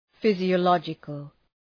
Προφορά
{,fızıə’lɒdʒıkəl}